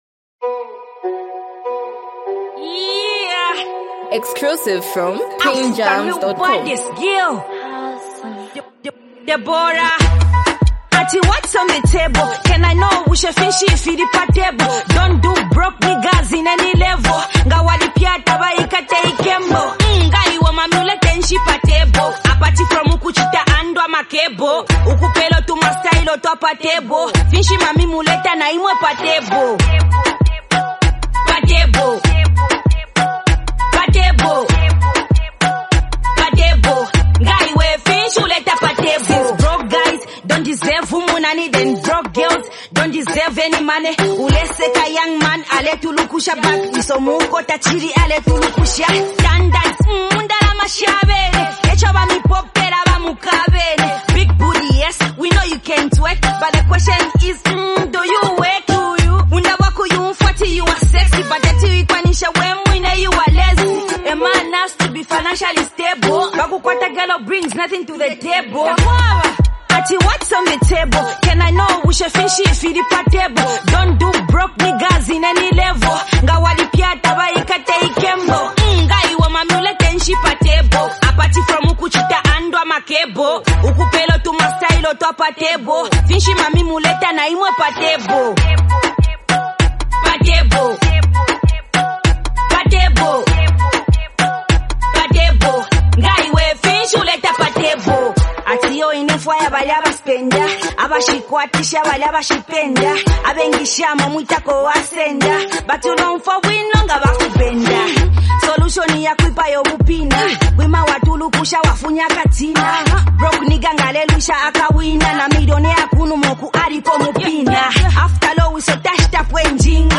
a bold and confident song
both motivational and empowering